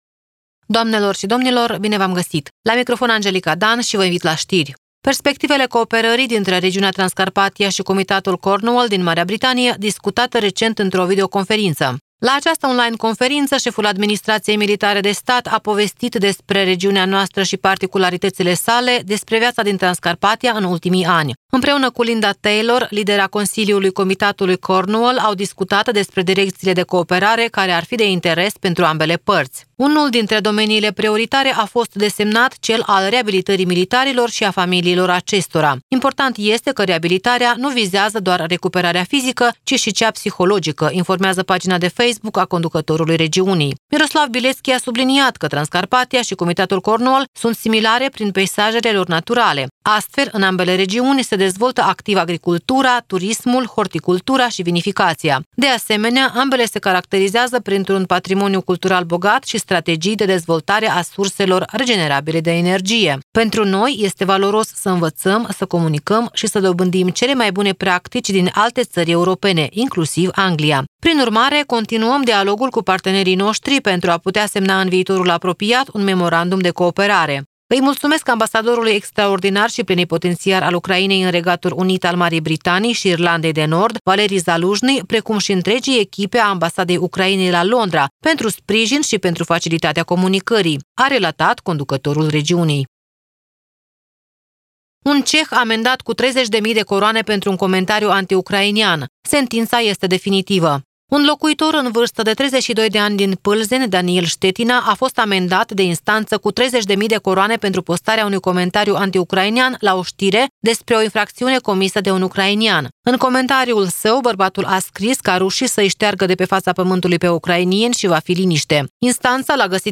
Știri Radio Ujgorod – 20.11.2024, ediția de seară